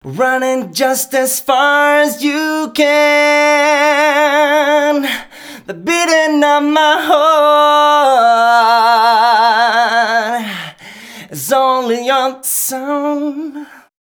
015 male.wav